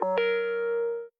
session-ios / Session / Meta / AudioFiles / messageReceivedSounds / input-quiet.aifc
input-quiet.aifc